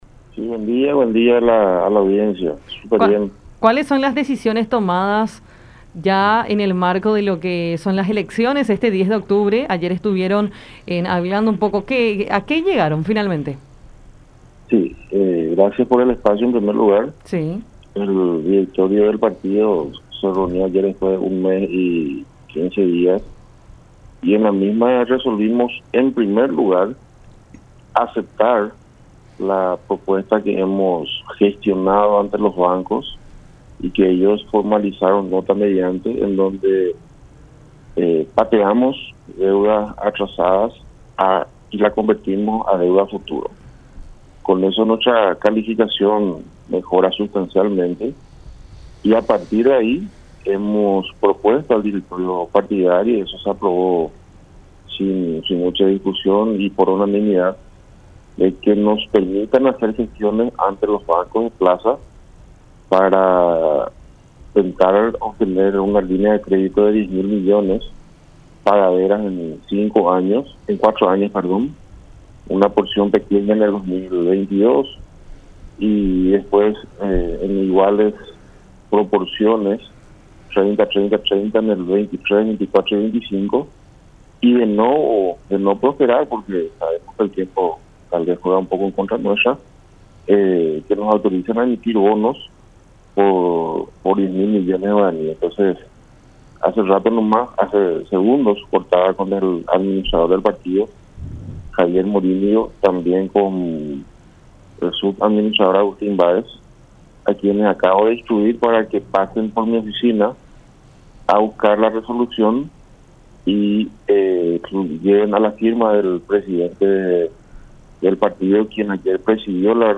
en diálogo con Enfoque 800 por La Unión.